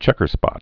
(chĕkər-spŏt)